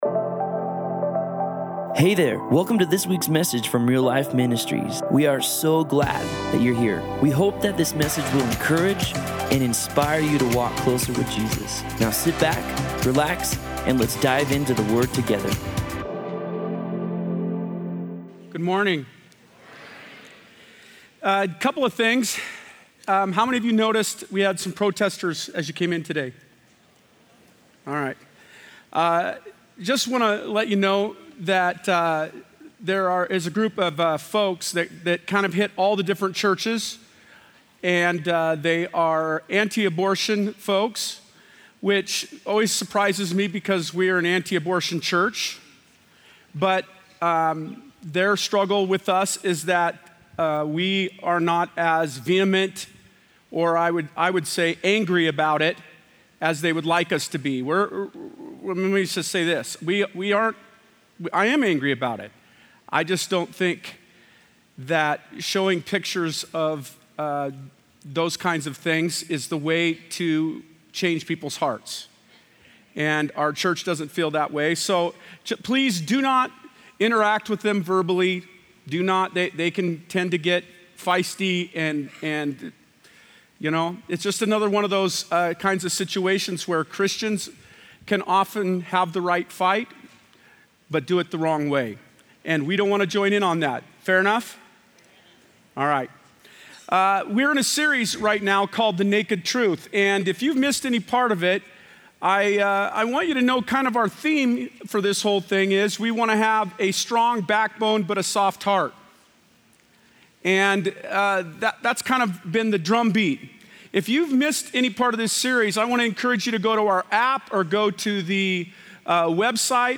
Post Falls Campus